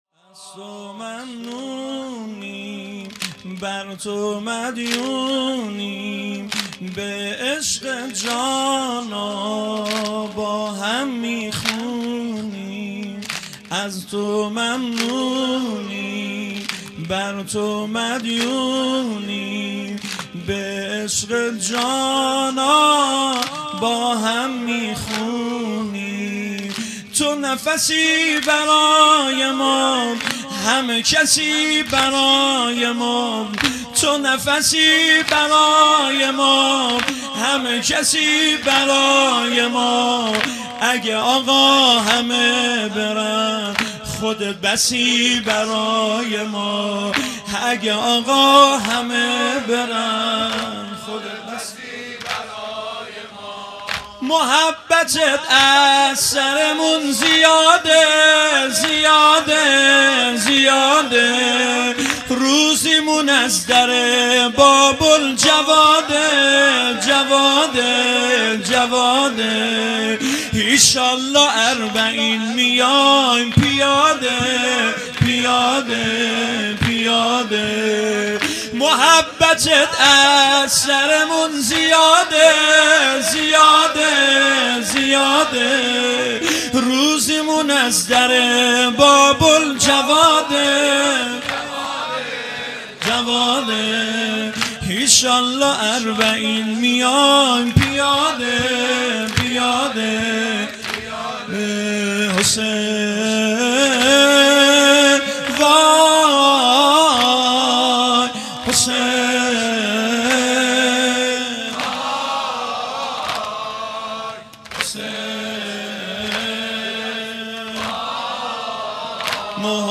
زمینه | از تو ممنونیم مداح
محرم الحرام ۱۴۴۱ ، ۱۳۹۸ شب سوم